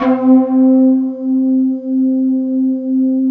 20 S.FLUTE-L.wav